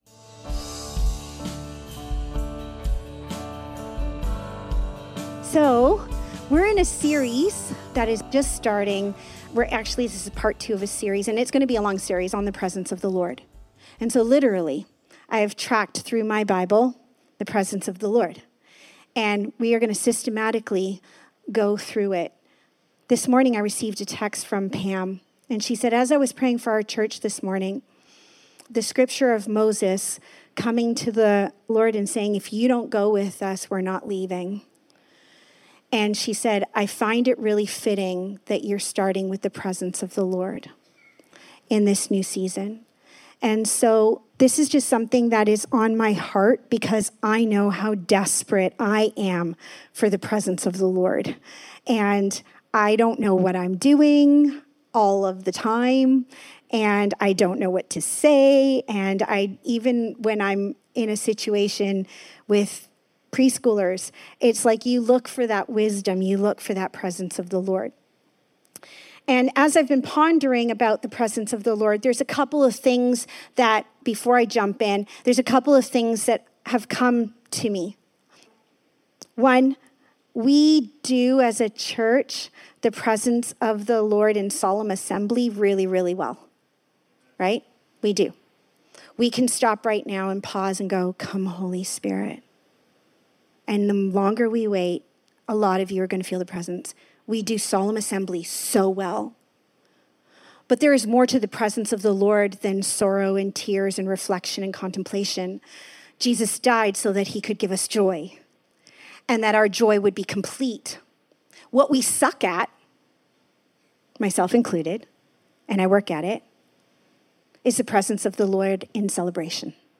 We are exploring how the presence of God is woven throughout Scripture, with a particular focus today on the story of Cain and Abel in Genesis 4. This sermon emphasizes the critical importance of understanding God’s timing, the need for both solemn reflection and joyful celebration in His presence, and the dangers of allowing pride and distractions to pull us away from Him.